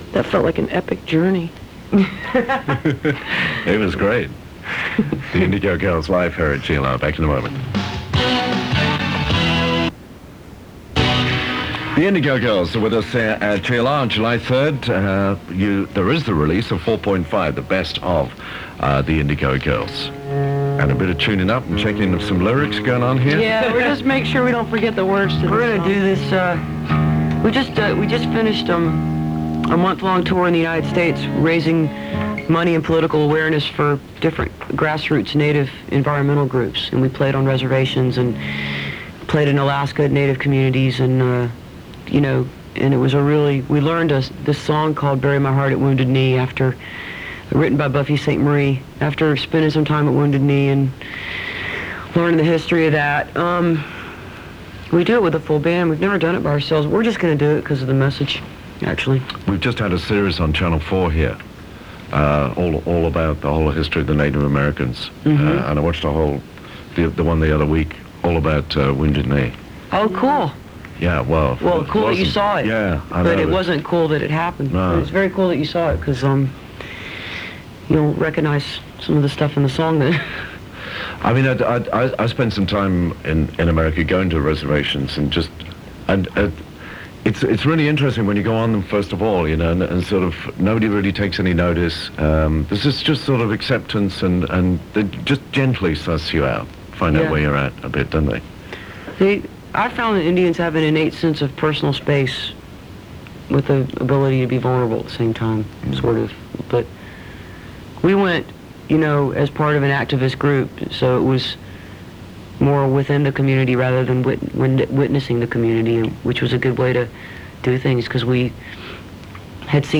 05. interview (3:29)